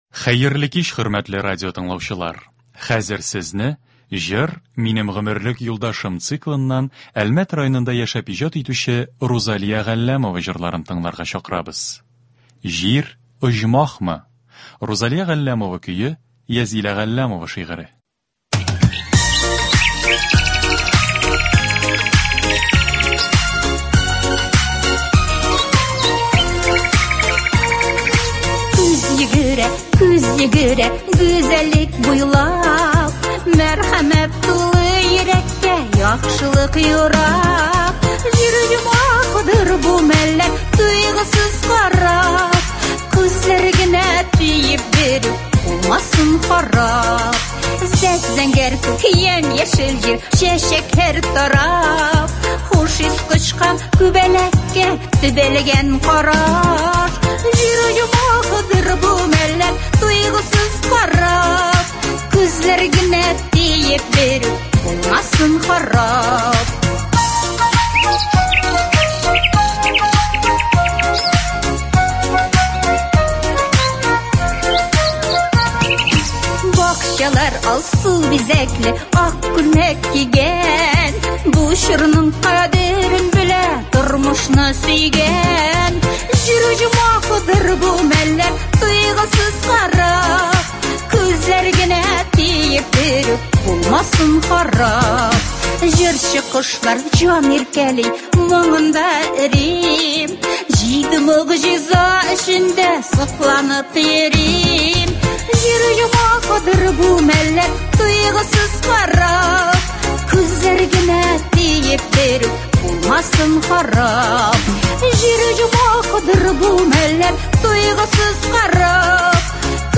Концерт (28.06.21)